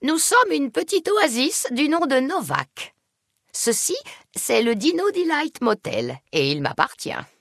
Catégorie:Dialogue audio de Fallout: New Vegas